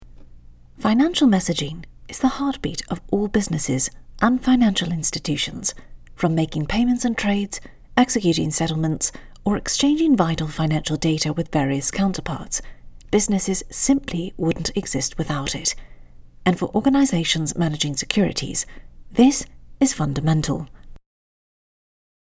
Finance Spot UK